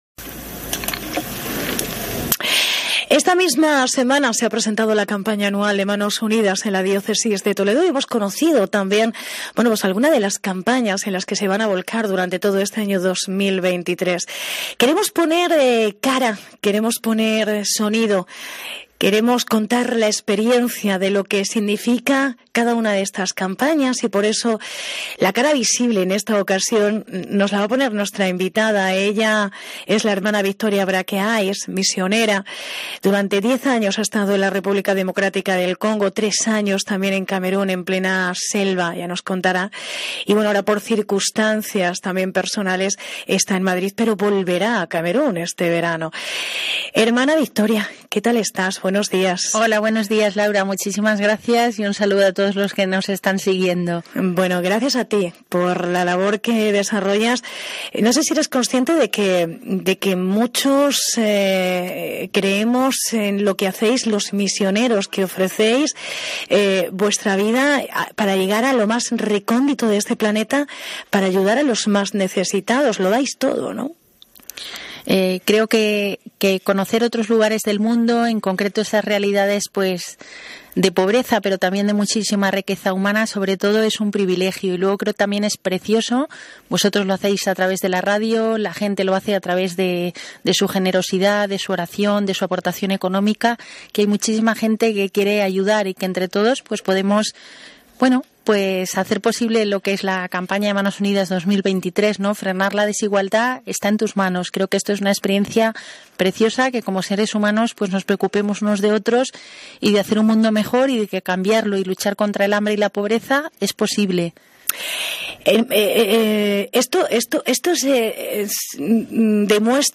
VÍDEO DE LA ENTREVISTA